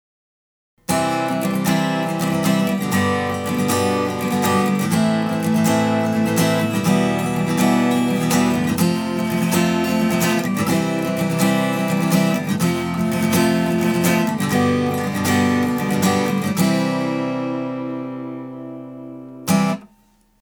コードをジャカジャカするなら薄いピックでやってみません？
薄いピック
薄いピック：0.46mm
薄いピックは高音域がでてるので煌びやかな音がしますよね。
やわらかいくせになかなかいい音鳴るじゃん・・・って感じですよね。